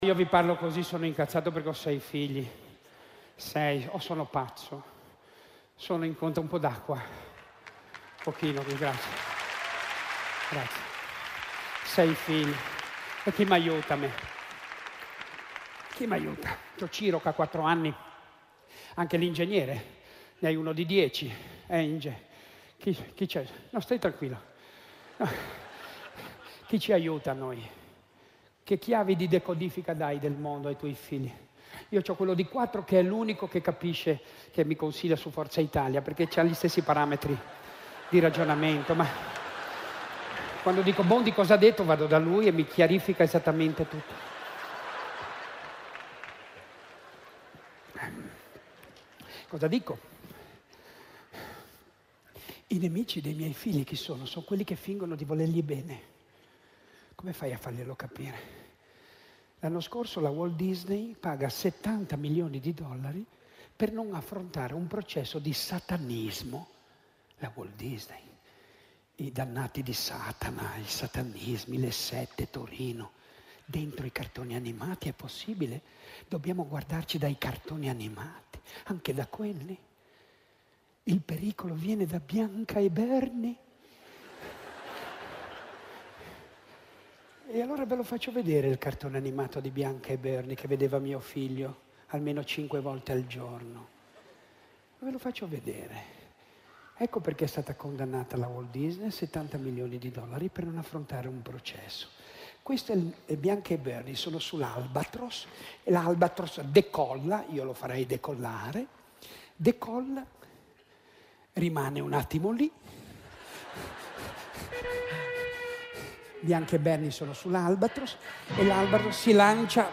Ci è stato segnalato il fatto che il celebre uomo di spettacolo Beppe Grillo ha preso a cuore la difesa dal subliminale dei più piccoli, ed ha citato il caso del cartone animato Bianca e Bernie in un suo spettacolo realizzato in collaborazione con la televisione della Svizzera italiana. Beppe Grillo parla addirittura di 70 milioni di dollari pagati dalla Walt Disney per ...NON COMPARIRE in un processo che la vedeva imputata proprio per questo messaggio subliminale, nel quale lui, ed i giudici del processo, vedono anche un contenuto satanico.